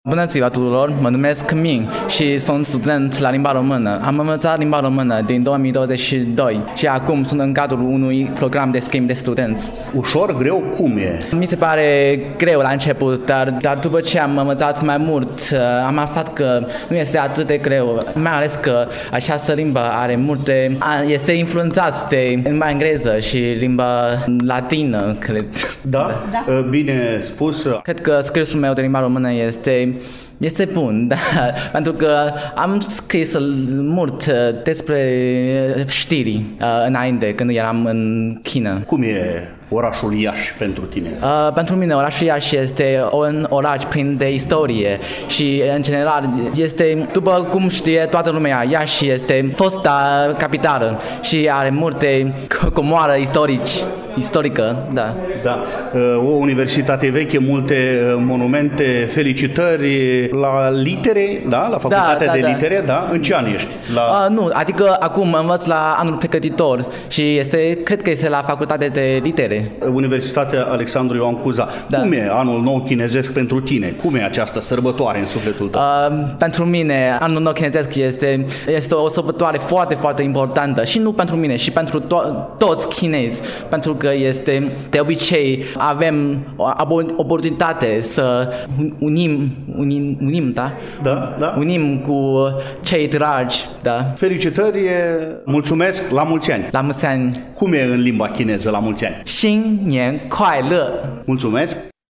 Dacă în două ediții trecute ale emisiunii noastre am vorbit despre Anul Nou Chinezesc, cu accent pe câteva activități culturale organizate cu acest prilej atât în incinta Fundației EuroEd din Iași, cât și în incinta Universității de Medicină și Farmacie „Gr. T. Popa” Iași, astăzi continuăm a difuza câteva înregistrări din timpul sărbătorii pe care am amintit-o.